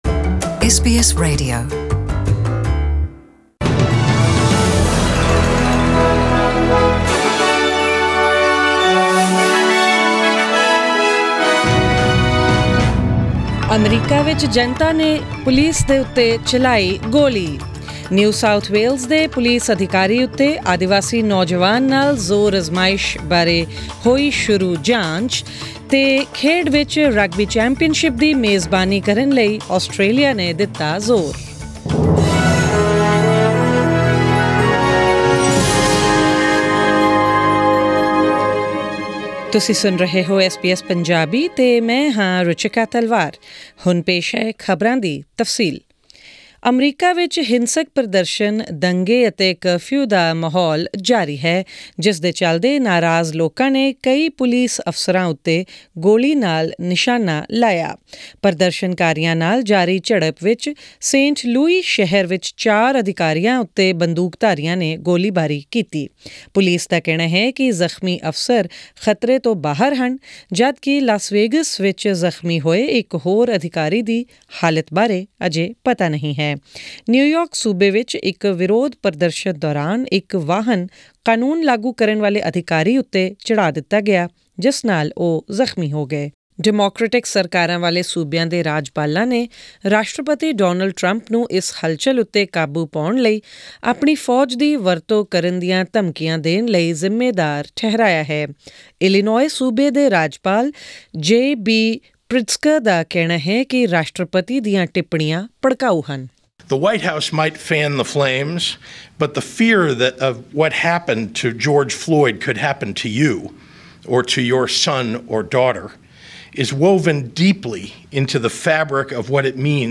In this bulletin...